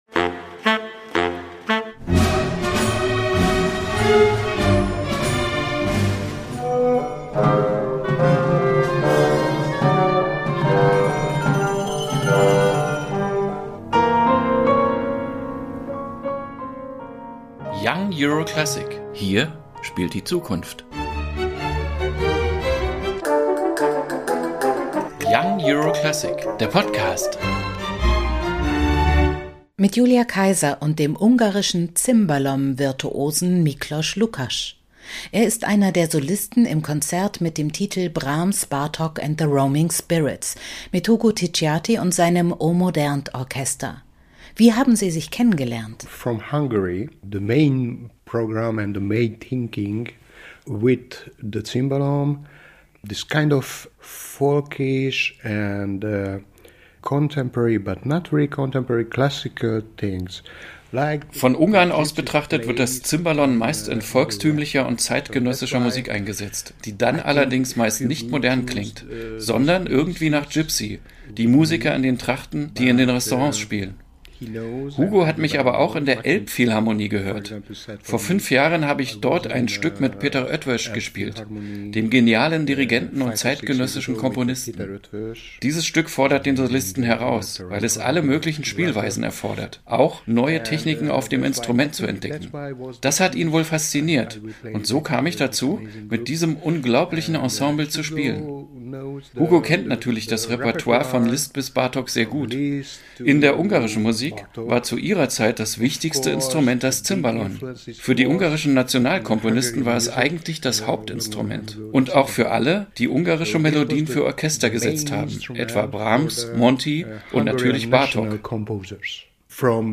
Konzerteinführung 13.08.2022 | O/Modernt New Generation Orchestra